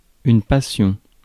ÄäntäminenFrance : « une passion »:
• IPA: [yn pa.sjɔ̃]